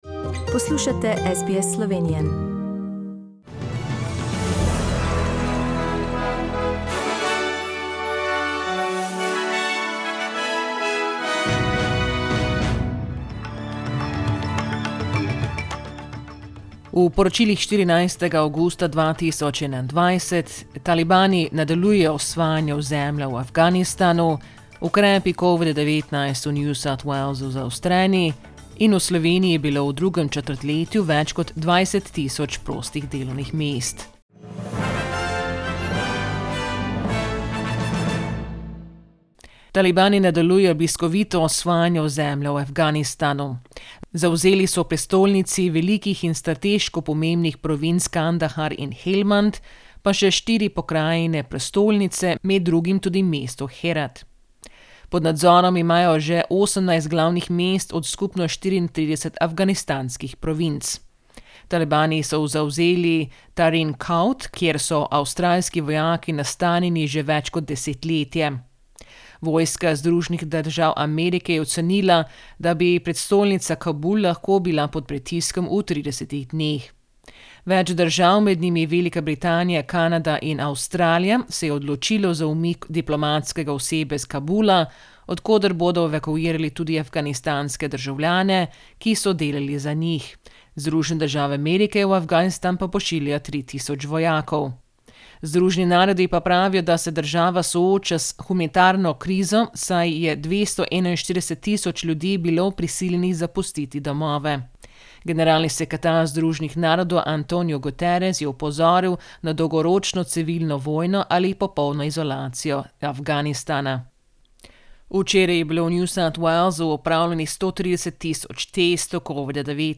SBS News in Slovenian - 14th August, 2021